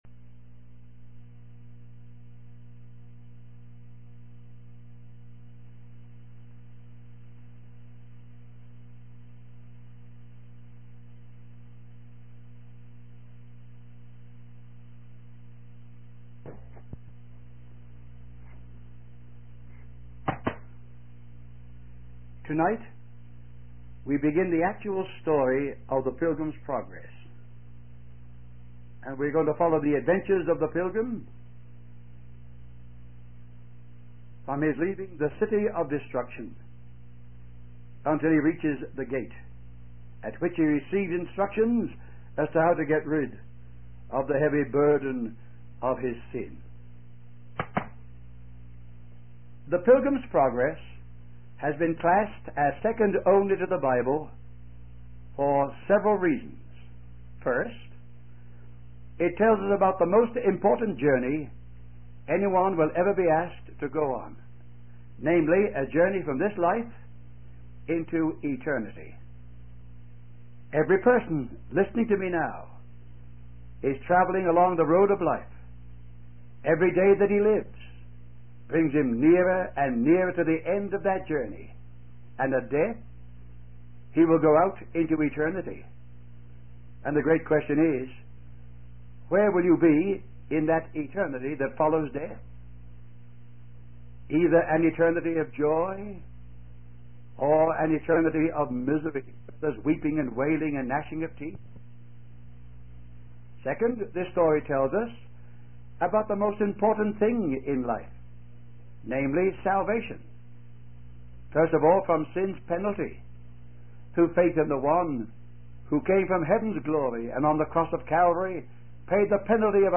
In this sermon, the preacher tells the story of a man named Graceless who is burdened by his sins and desperately seeks salvation.